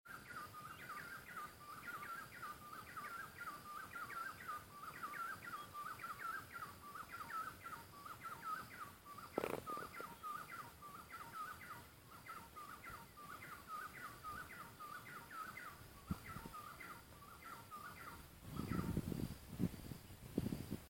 Chestnut Wood Quail (Odontophorus hyperythrus)
Varios grupos en diferentes quebradas cantando
Detailed location: Dapa
Condition: Wild
Certainty: Recorded vocal
Odontophorus_Hyperythrus.mp3